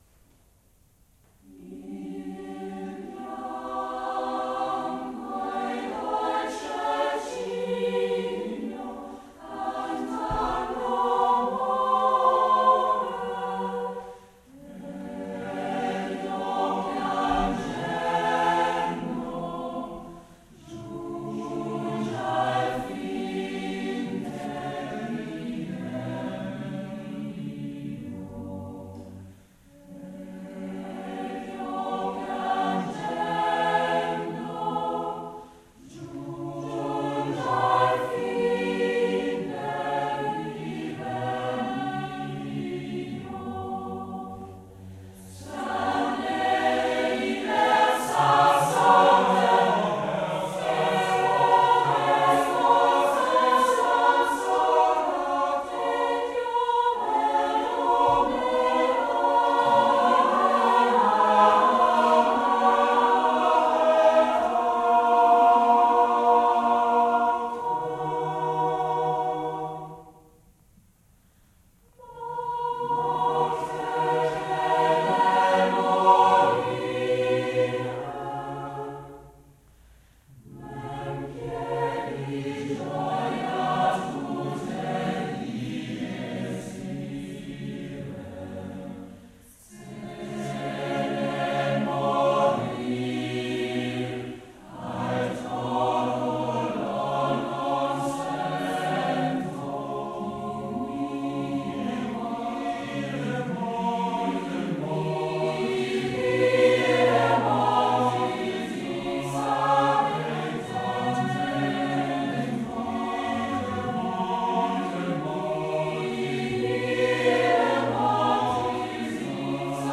Il bianco e dolce cigno uniChor 05.07.2005 Unikonzert '05